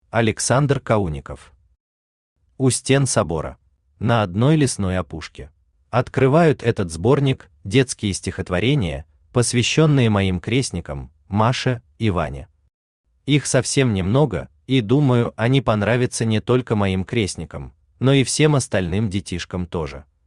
Аудиокнига У стен собора | Библиотека аудиокниг
Aудиокнига У стен собора Автор Александр Сергеевич Каунников Читает аудиокнигу Авточтец ЛитРес.